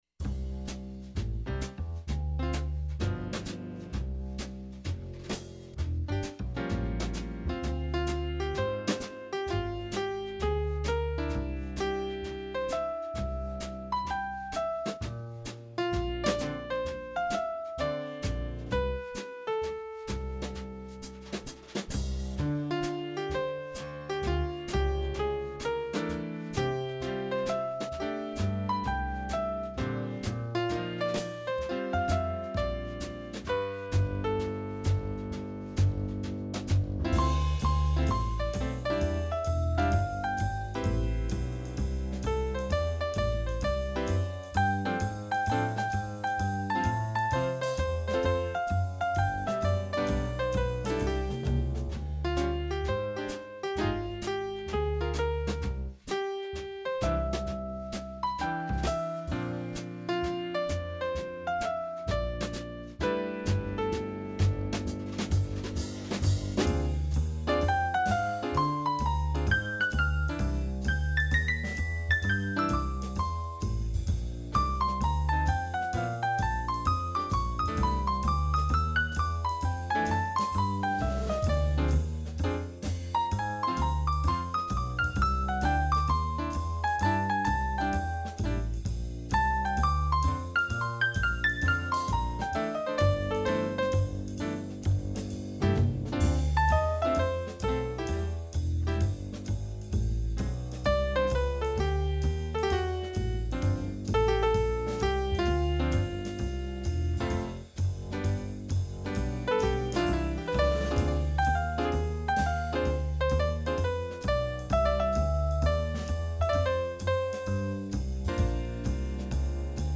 「4/4拍子のKeithピアノトリオ風モダンジャズ。小節をまたがったフレーズをエキサイトなリズム
で演奏。aではベースはくずれた2ビート､ピアノは左手で和音。bではベースはウォーキング｡」
キース・ジャレット風のジャズに仕上がっているかどうかは微妙ですが、ピアノトリオのジャズっぽい曲にはなったかなと思います。